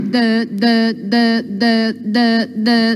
Play, download and share Dut Dut Dut original sound button!!!!
dutdutdut.mp3